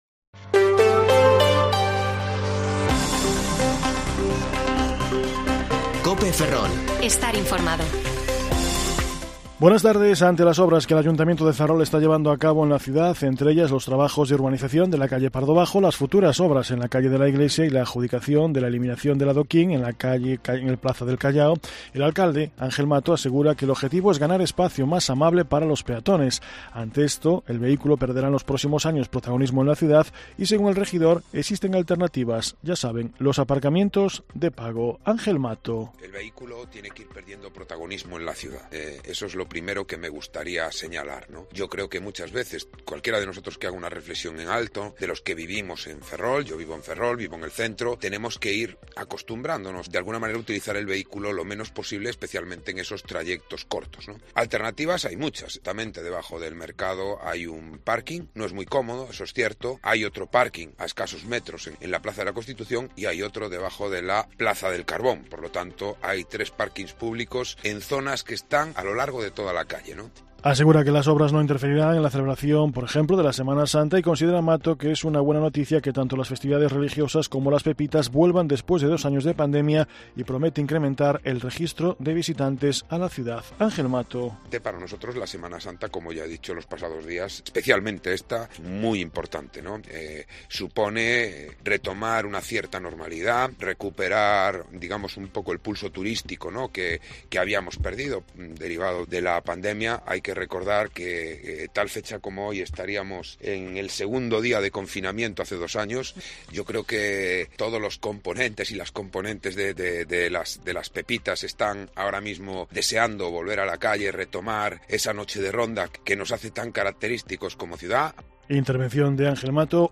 Informativo Mediodía COPE Ferrol 15/3/2022 (De 14,20 a 14,30 horas)